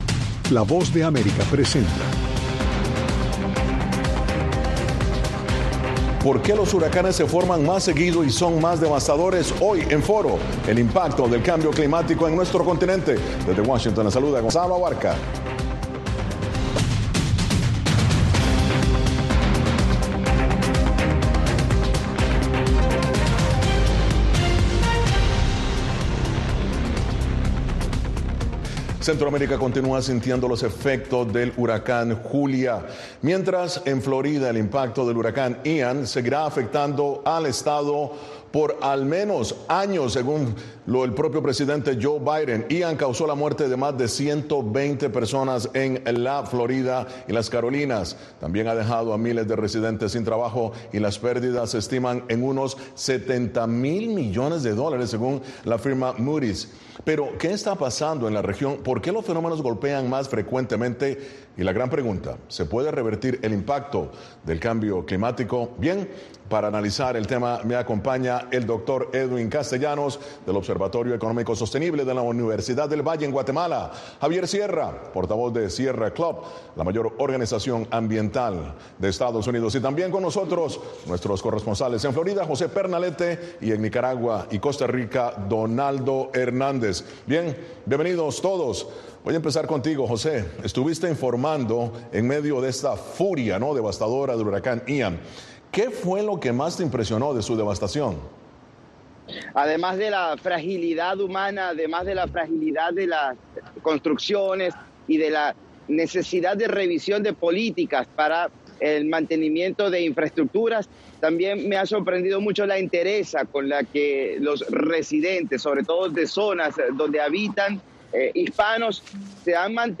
Foro (Radio): ¿Puede revertirse la crisis climática?